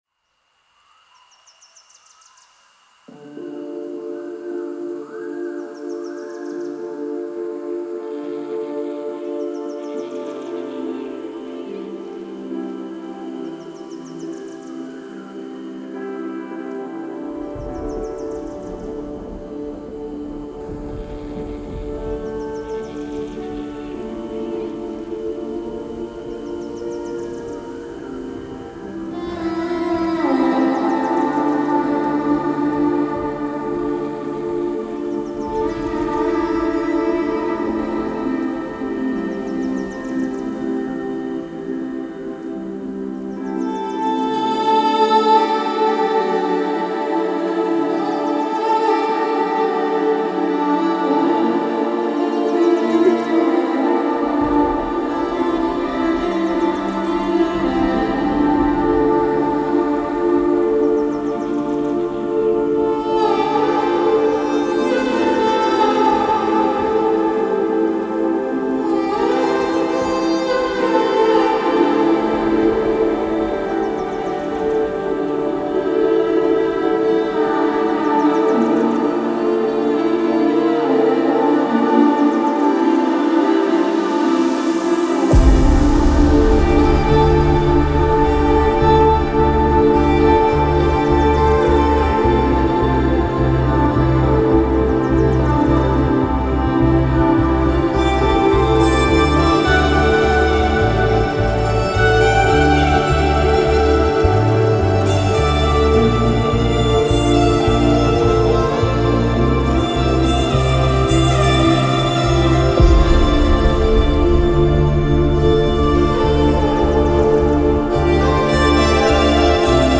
All TAMIL LOFI REMIX